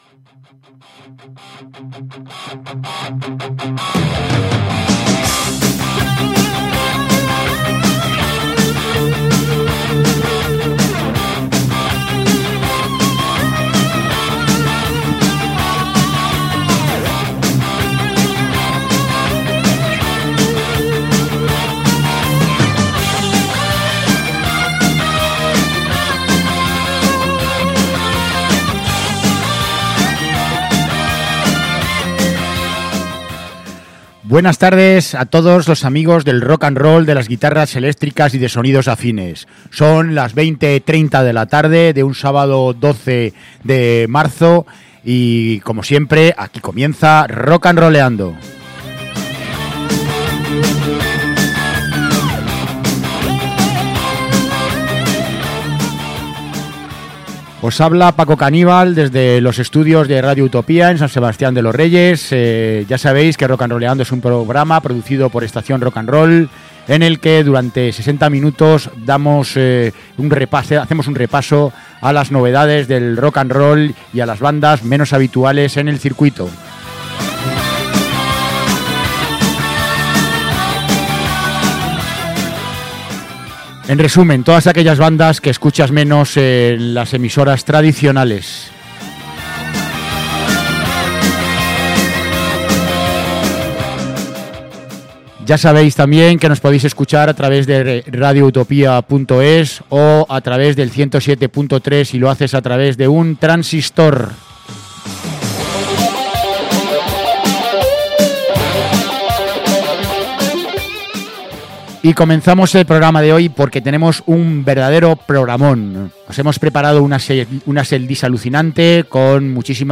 Con un sonido muy hard rock al estilo de Van Halen.
stoner